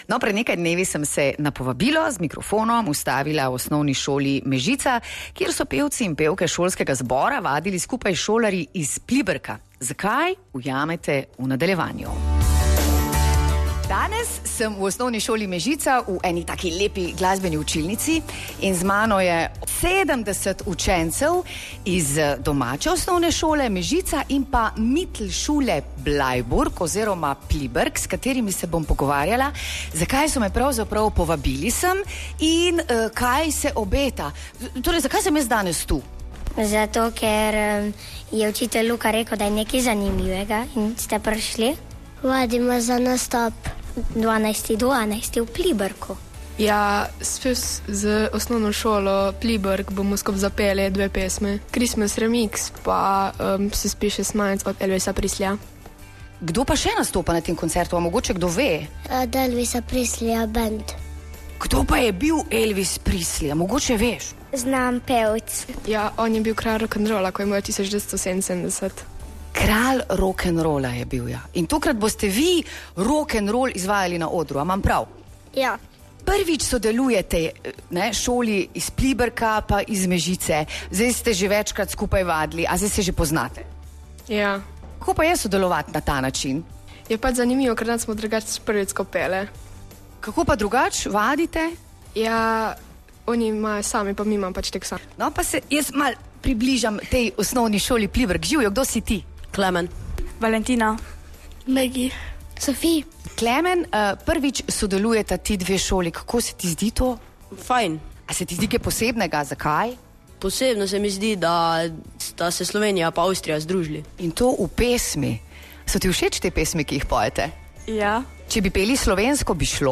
Združeni v zboru bodo nastopali na koncertu v četrtek, 12.12.2024 v Kulturnem domu Pliberk ob 19.30. Sodelovali bodo namreč na koncertu ROCK&ROLL Christmas, ki bo posvečen tudi pesmim Elvisa Presleya. Vse o dogodku in sodelovanju otrok čez mejo v reportaži